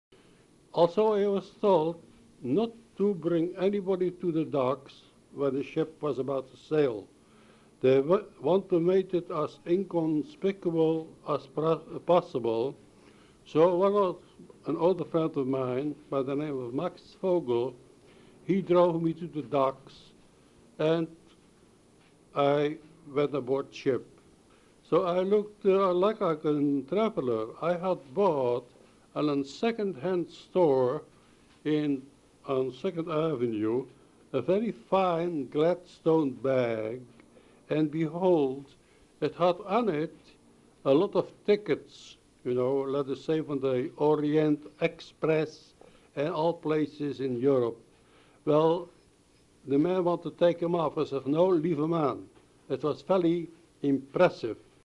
on a set of crackling, hissing reel-to-reel tapes that no one had played in years.
an old voice speaking English with an unmistakable Dutch accent.